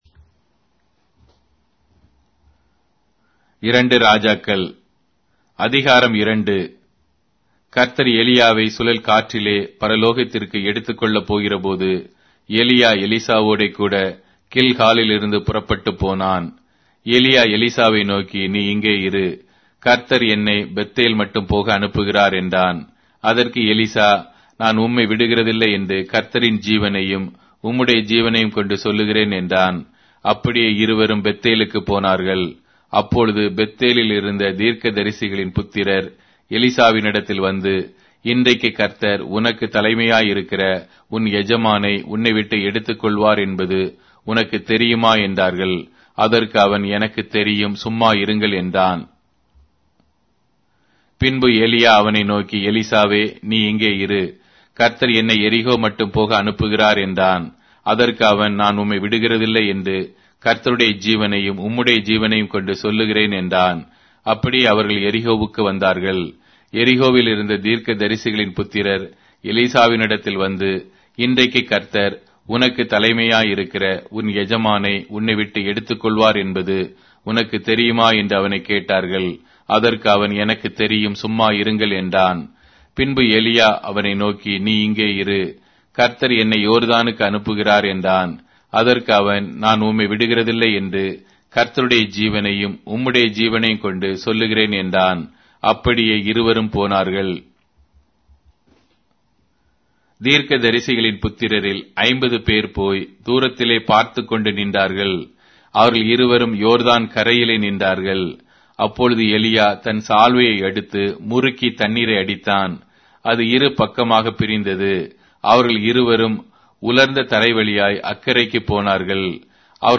Tamil Audio Bible - 2-Kings 12 in Wlc bible version